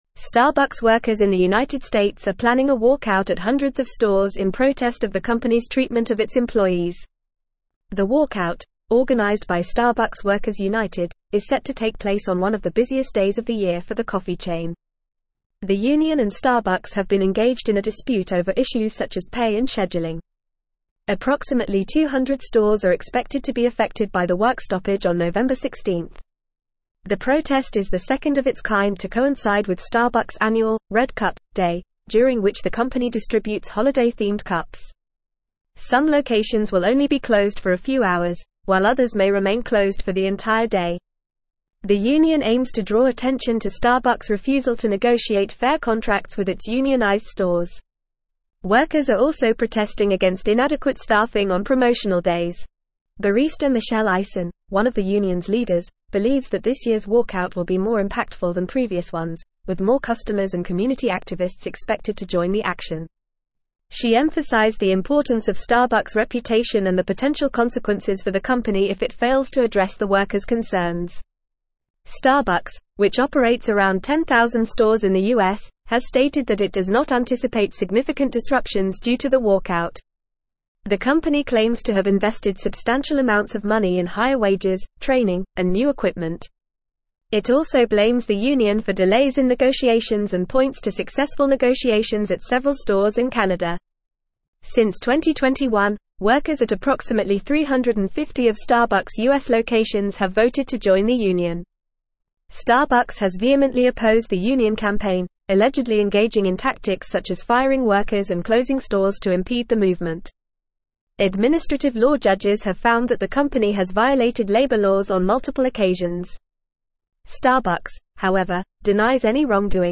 Fast
English-Newsroom-Advanced-FAST-Reading-Starbucks-workers-plan-walkout-over-pay-and-conditions.mp3